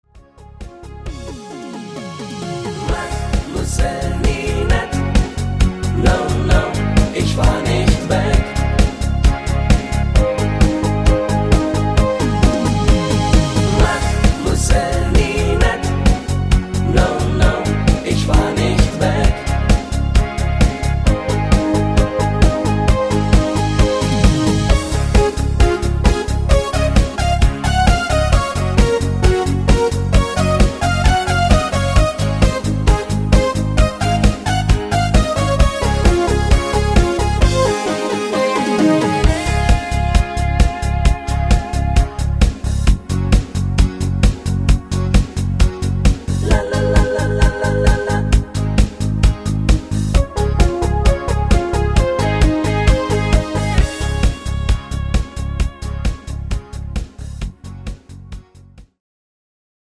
Discofox